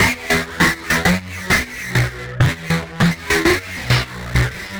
tx_synth_100_swerve_EMin.wav